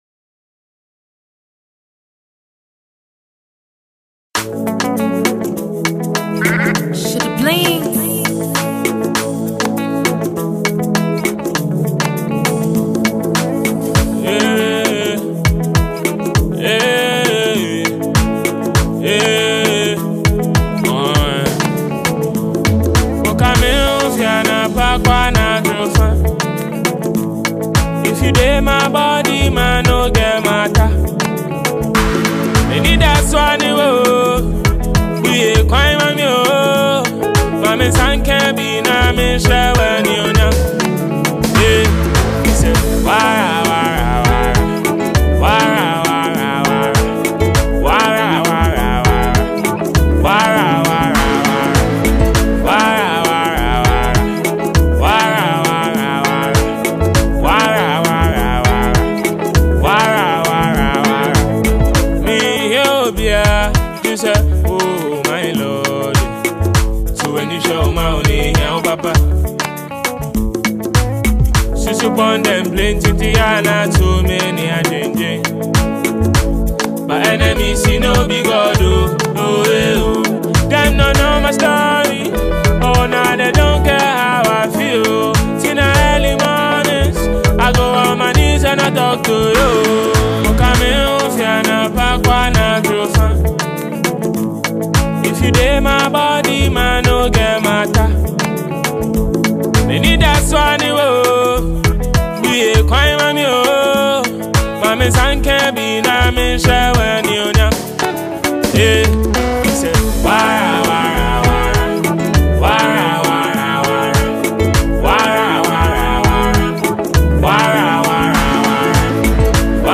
goes low tempo
gospel song